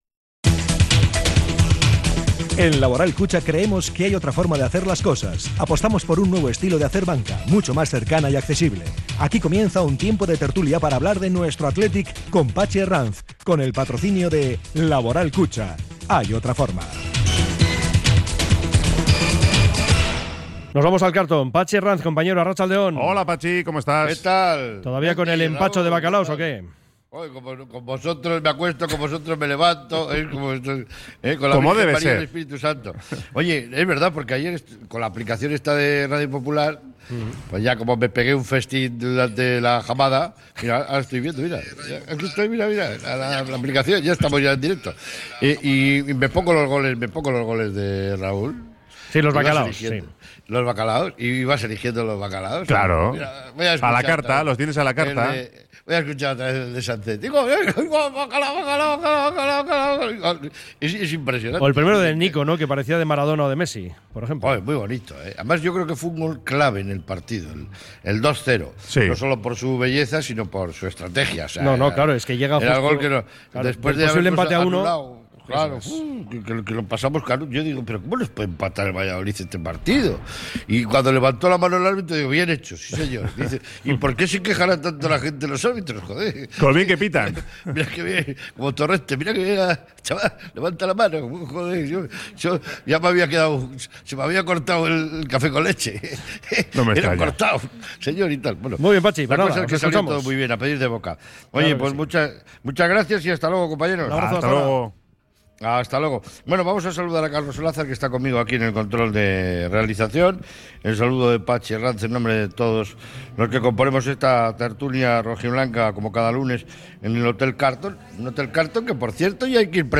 y sus invitados desde el Hotel Carlton